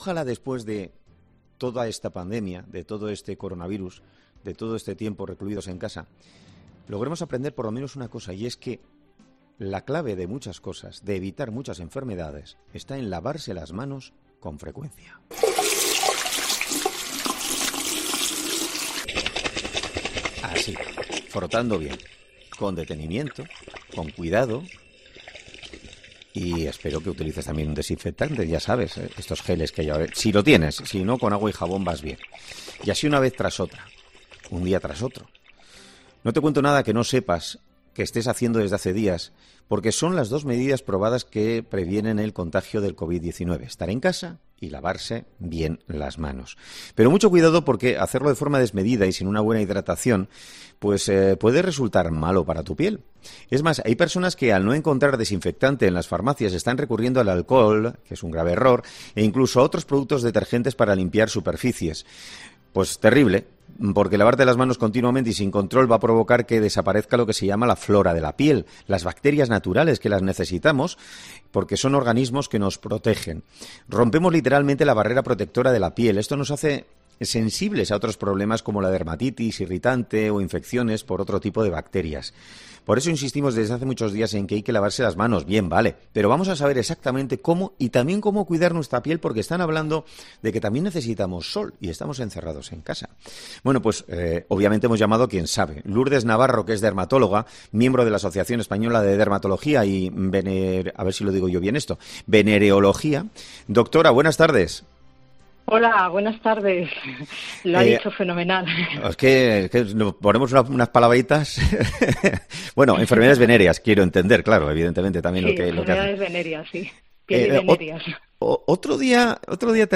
Para conocer cómo hay que proceder correctamente, este lunes ha sido entrevistada en 'Herrera en COPE'